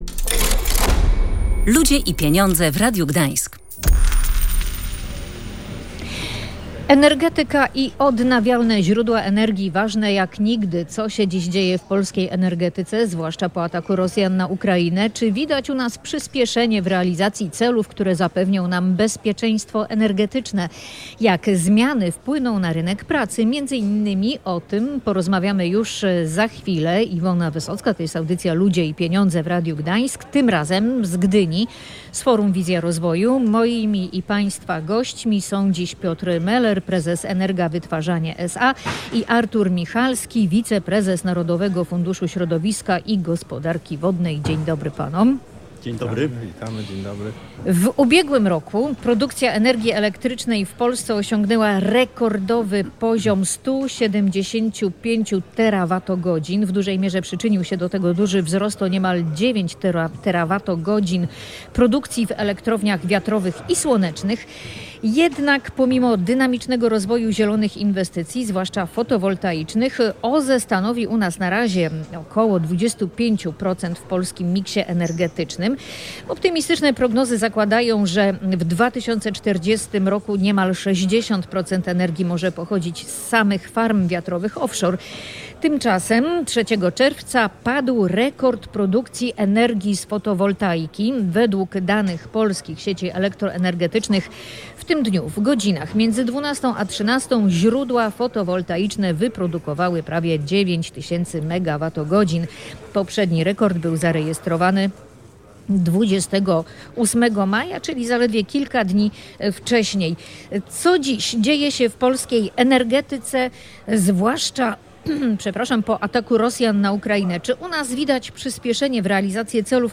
Podczas Forum Wizja Rozwoju o przyśpieszeniu w realizacji celów, które zapewnią nam bezpieczeństwo energetyczne,